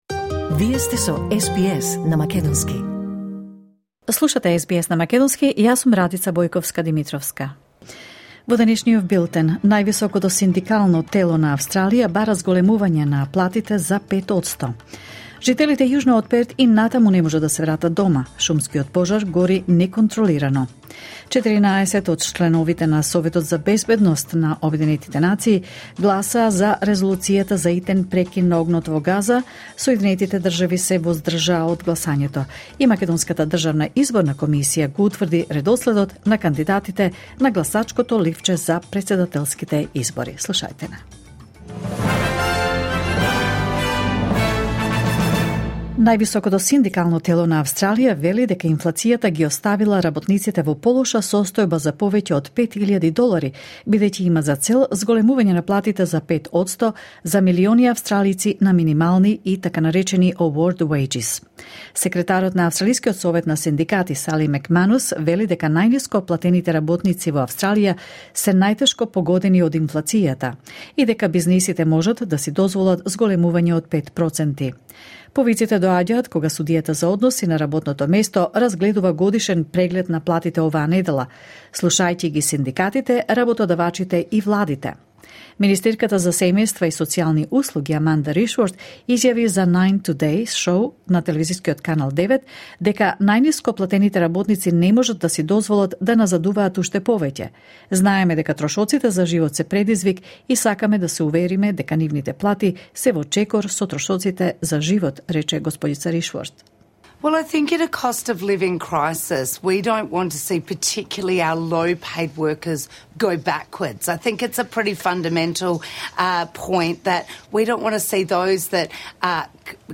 SBS News in Macedonian 26 March 2024